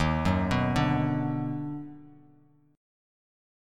D#mM7#5 chord